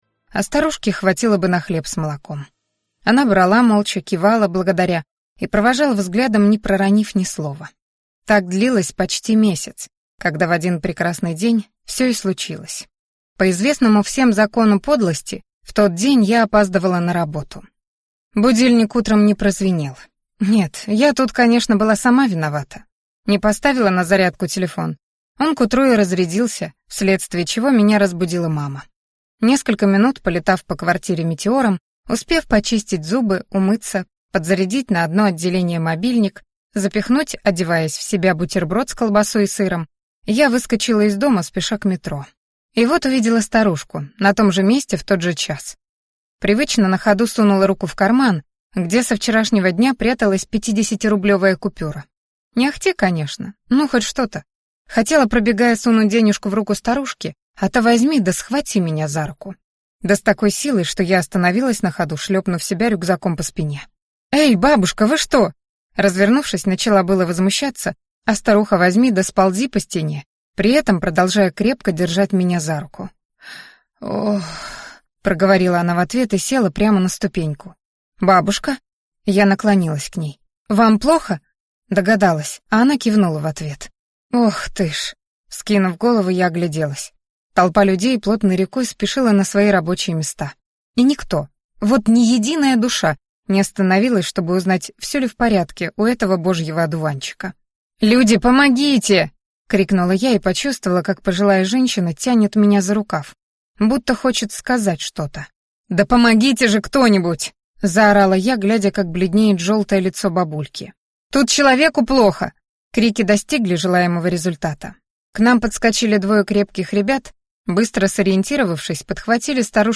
Аудиокнига Ведьмина сила | Библиотека аудиокниг